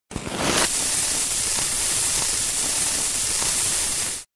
Звук петард
Зажигание запала и горение фитиля